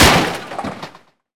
bigLogCut.wav